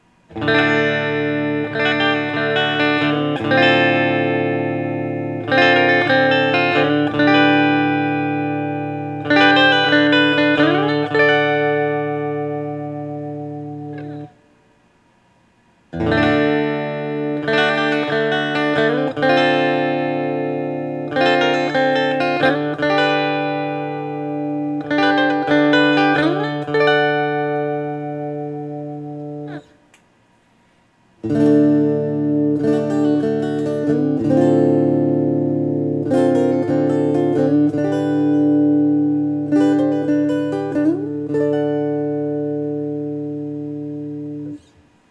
For these recordings I used my normal Axe-FX Ultra setup through the QSC K12 speaker recorded into my trusty Olympus LS-10.
For this guitar I recorded each selection with the tone set to 10, then 7, then 0.